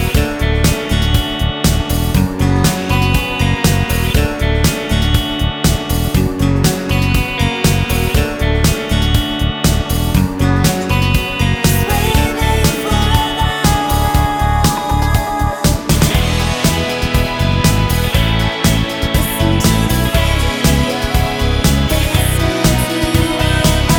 No Backing Vocals Irish 4:14 Buy £1.50